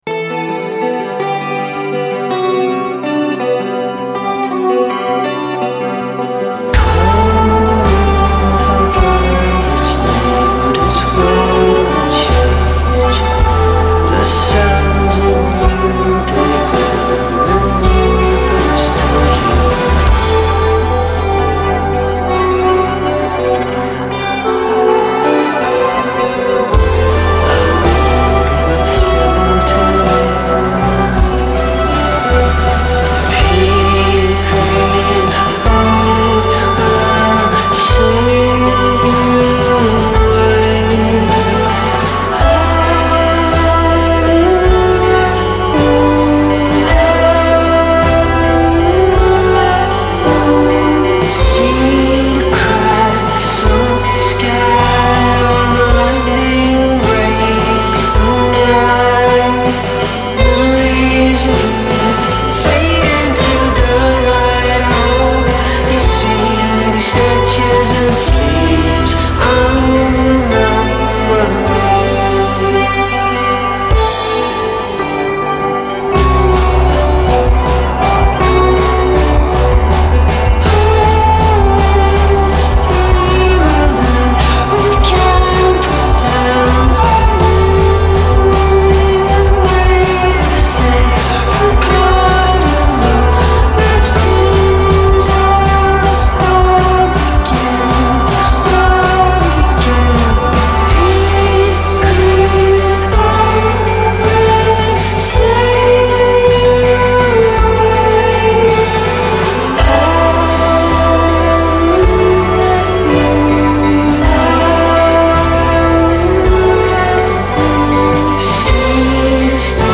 Irish atmospheric indie-pop duo
deliciously melancholic new single